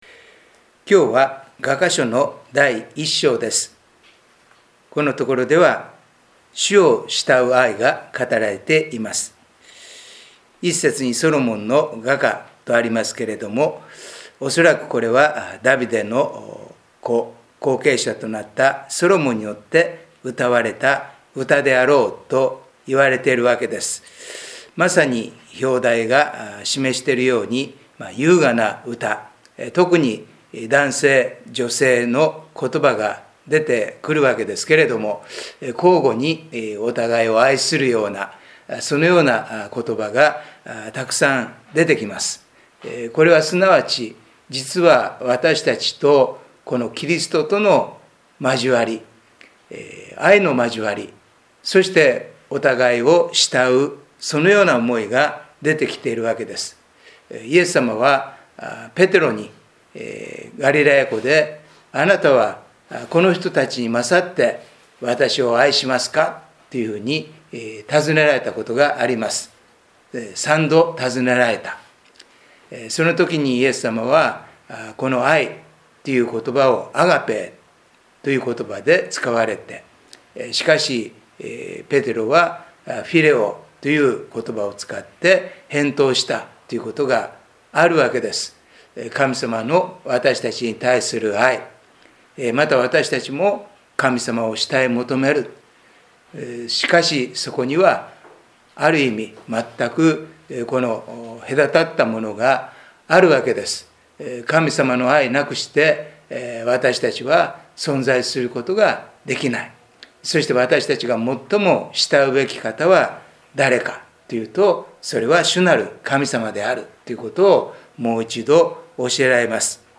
音声メッセージです。